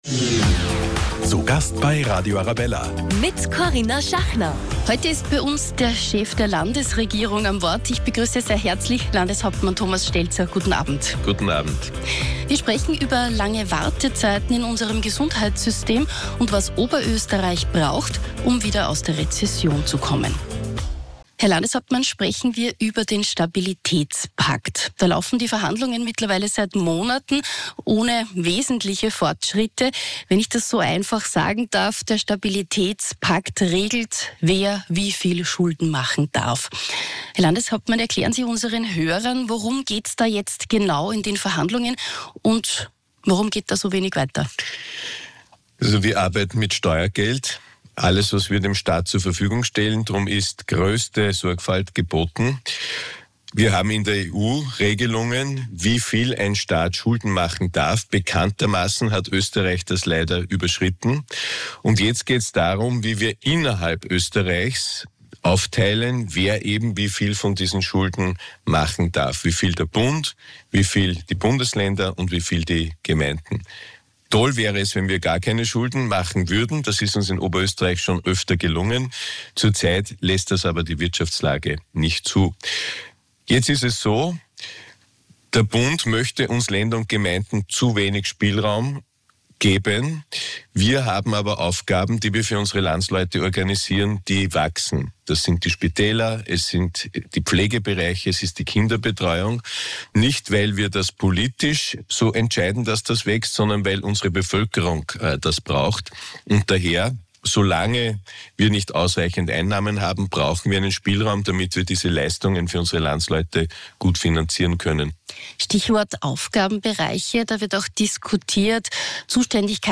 Zu Gast ist der Landeshauptmann Thomas Stelzer.